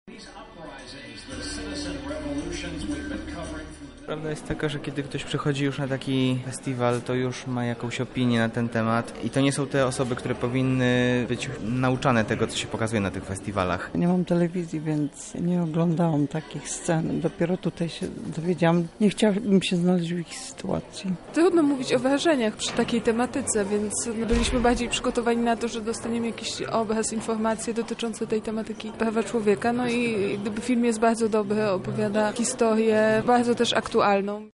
Watchdocs relacja